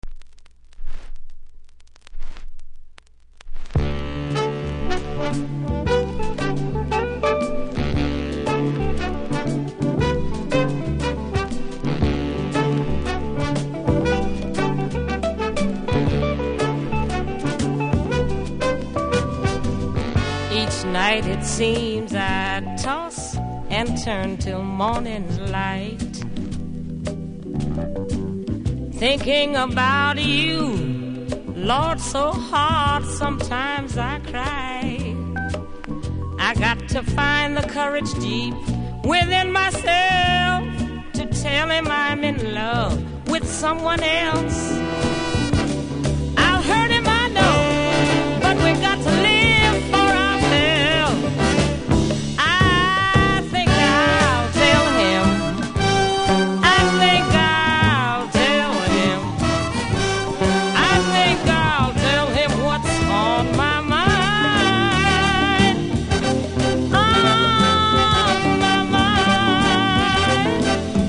70'S FEMALE
Vinyl
盤に熱変形があり無録音分でノイズ感じますが音が出れば気にならない程度なので問題無しかと思いますが試聴で確認下さい。